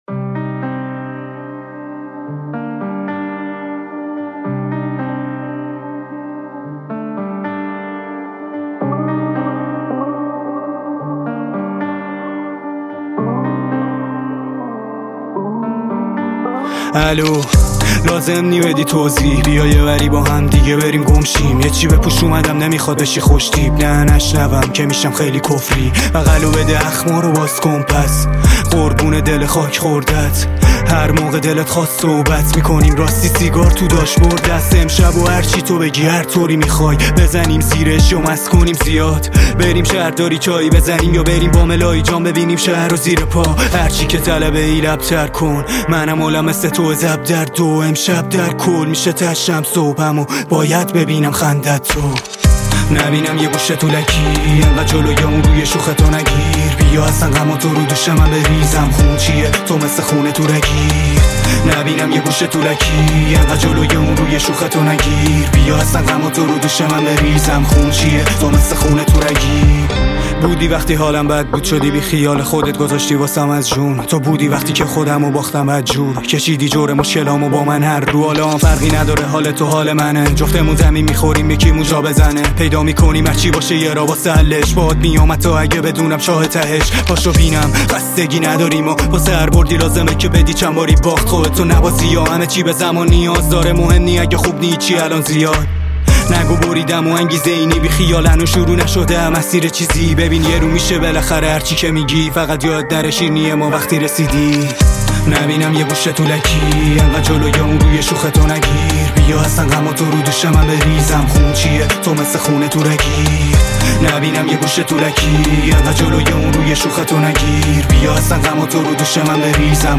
آهنگ با صدای زن
اهنگ ایرانی